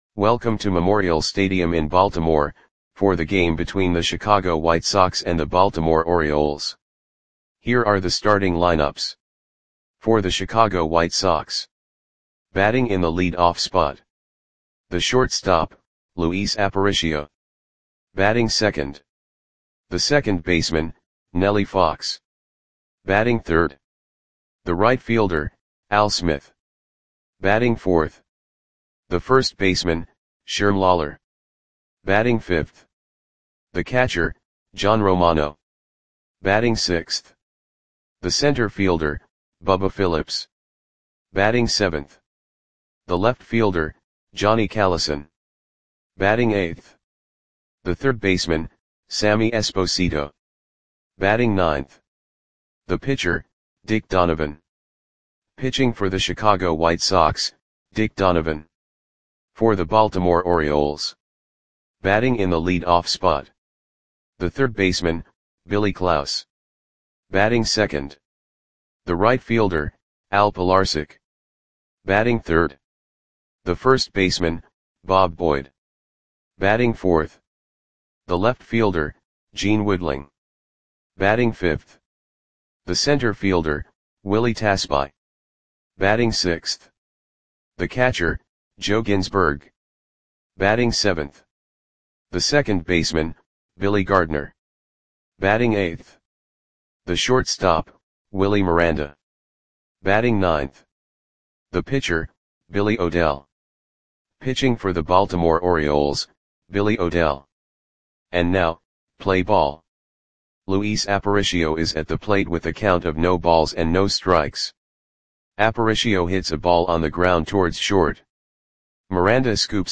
Audio Play-by-Play for Baltimore Orioles on June 14, 1959
Click the button below to listen to the audio play-by-play.